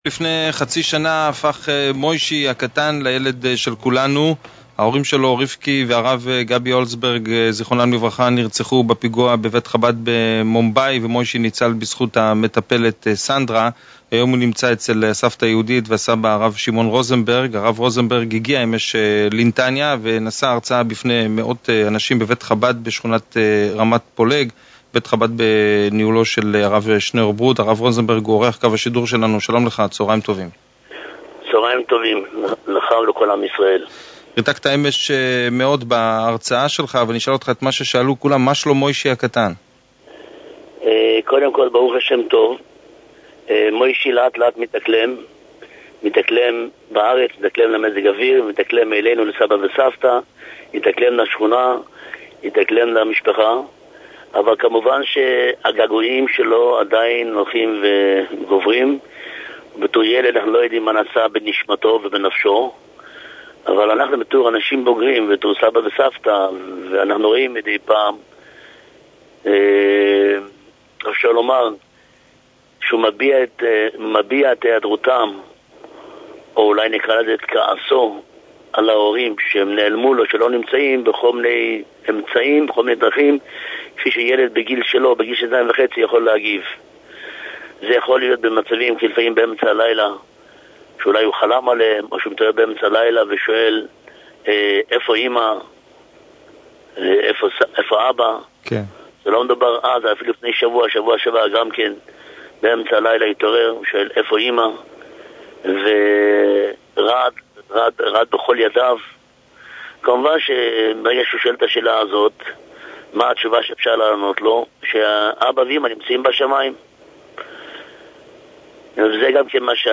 התראיין ביומן החדשות ברדיו אמצע הדרך (90fm)